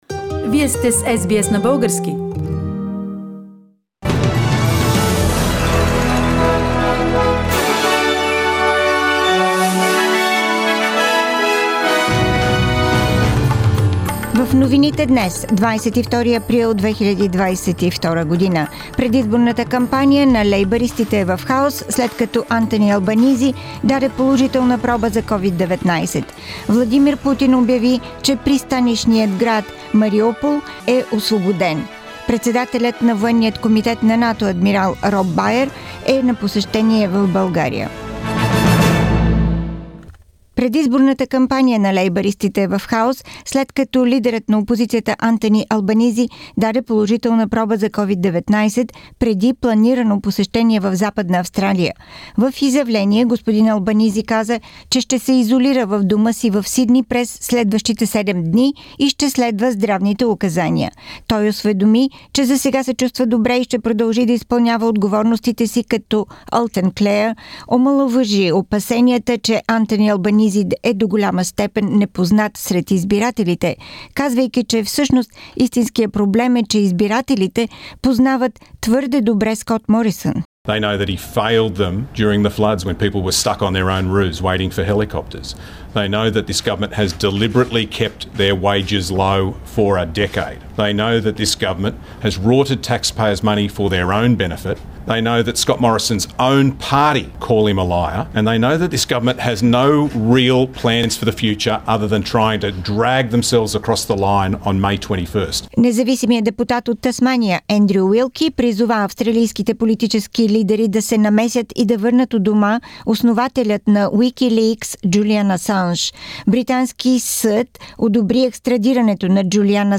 Weekly Bulgarian News – 22nd April 2022